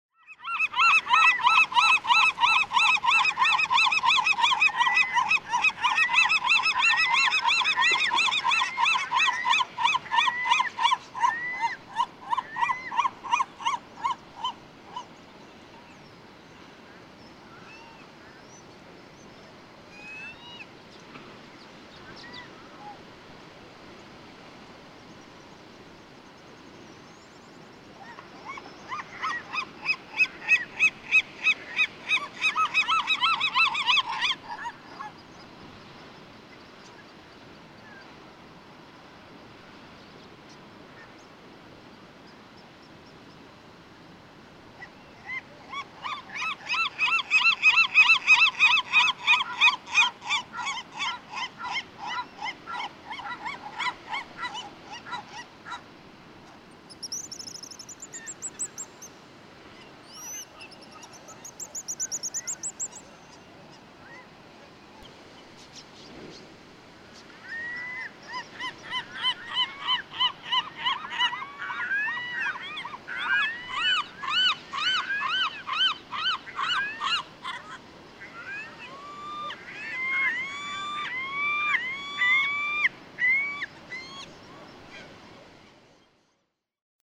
Western gull
These "long calls" are also believed to be innate; gulls also lack songbird-like "song control centers." Don't miss the high-pitched pigeon guillemot vocalizations (songs? calls?) from 0:57 to 1:03.
Seal Rock, Oregon.
113_Western_Gull.mp3